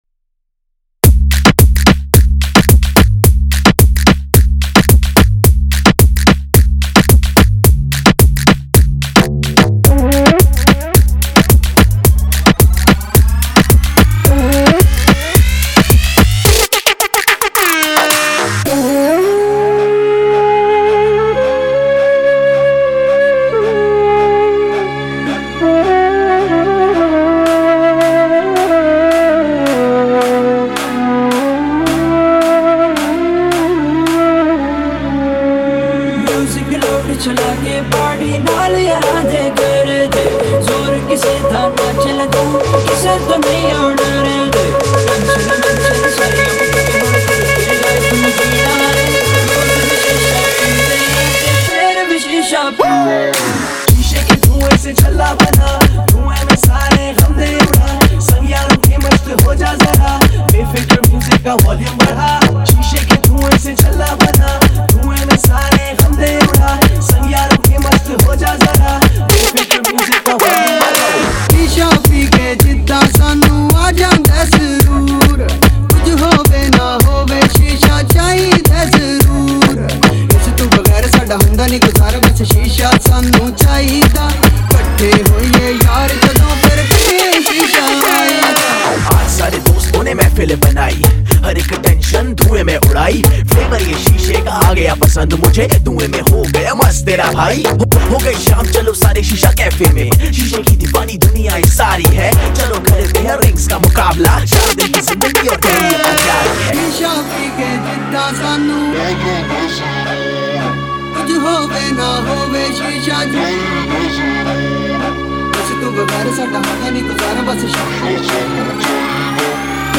это захватывающая трек в жанре электронной музыки